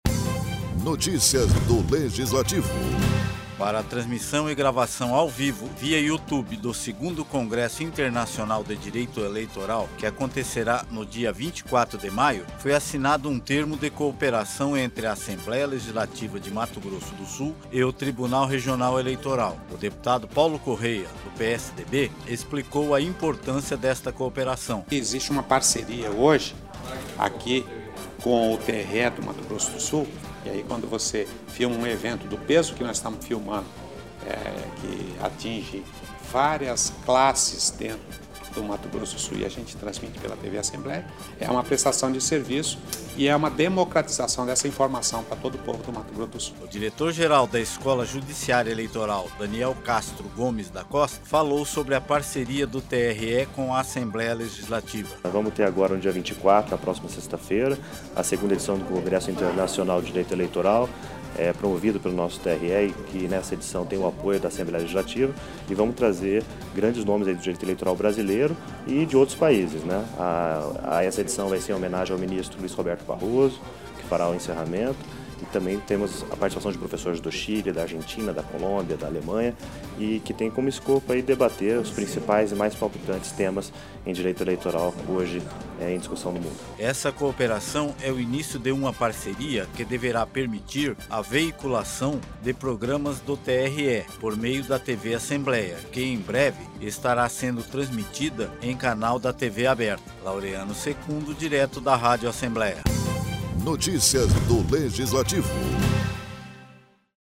O presidente da Casa de Leis, deputado Paulo Corrêa, do PSDB explicou a importância desta cooperação.